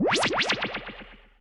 COMEDY - ZAP 02
Category: Sound FX   Right: Commercial